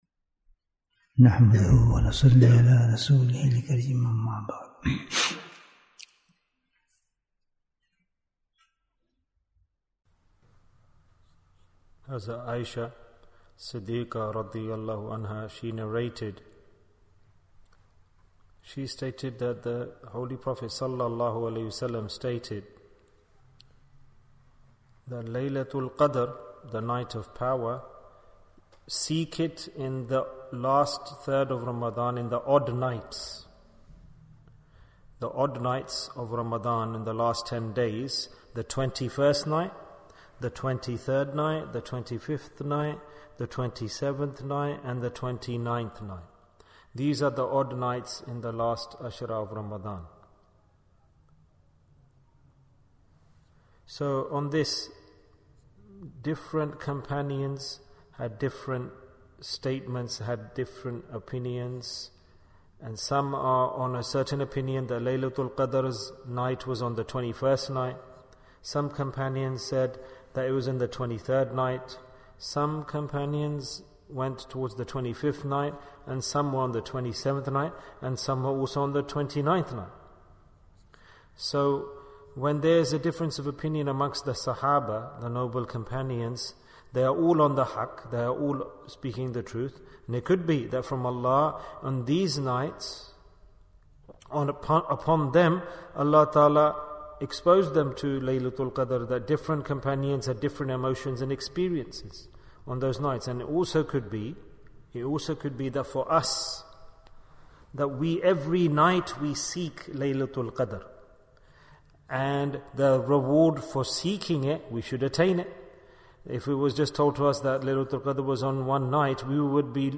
Which Ibaadah is to be Performed in the Night of Qadr? Bayan, 61 minutes17th April, 2023